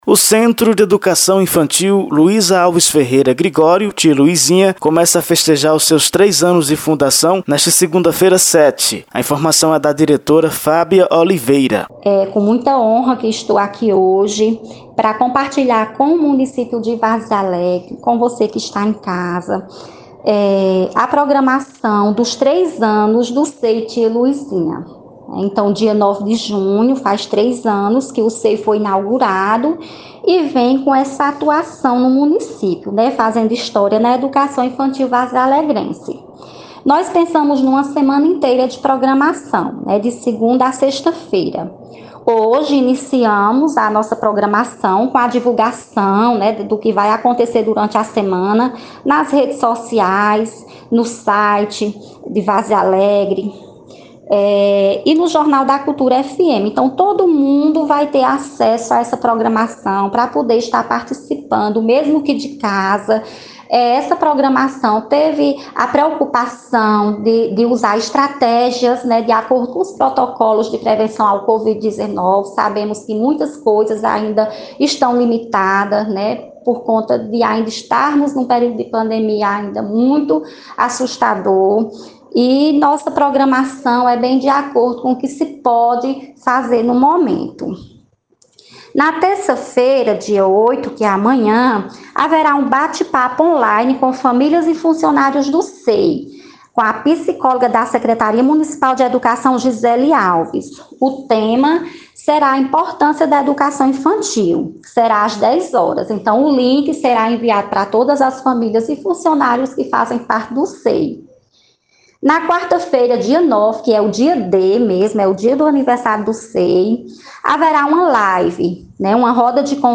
Confira o áudio da reportagem: foto rede sociais